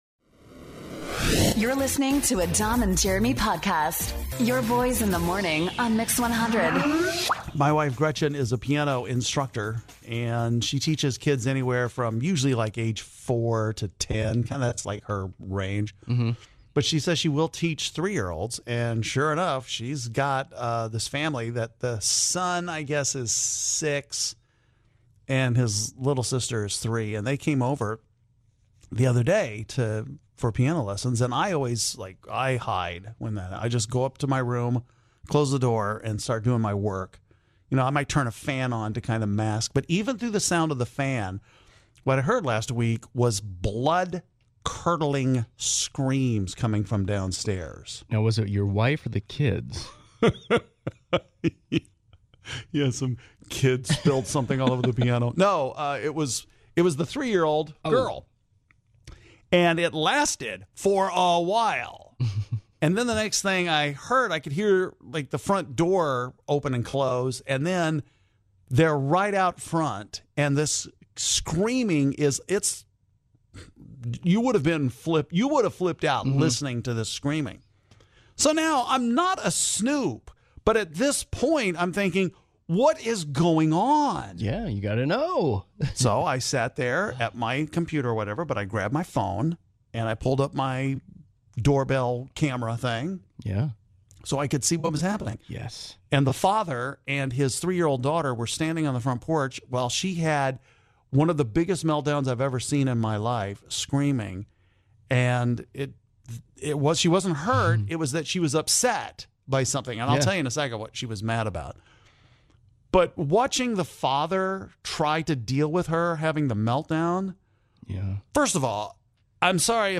Listeners describe the silly thing that caused a toddler meltdown.